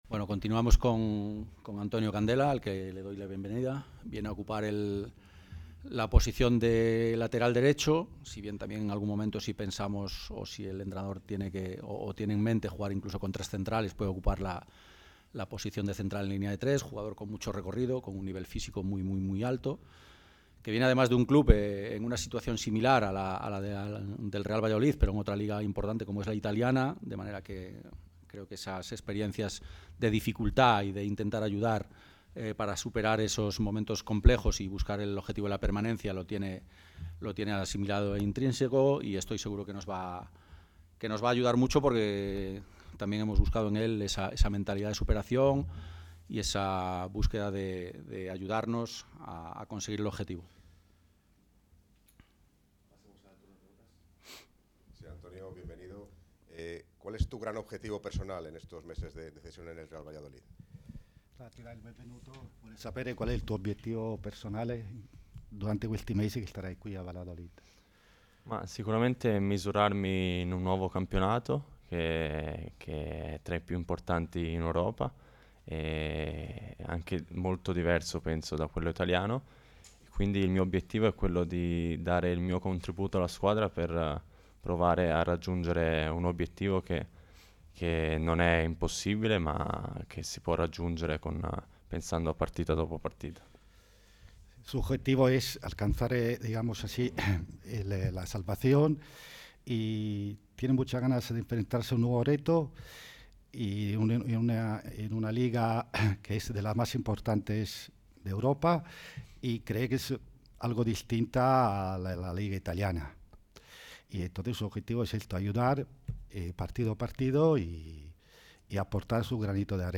Ruedas de prensa
La sala de prensa del Estadio José Zorrilla albergó en la mañana de este miércoles la cuádruple presentación de los últimos refuerzos del Real Valladolid en el mercado invernal.